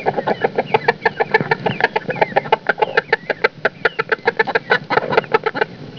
These are portraits of dearly departed chickens who lived with us; please enjoy some chicken noises recorded straight from the barn as you peruse!
Chicken Noises - Click to Listen Happy, hungry chicken!
chicken1.wav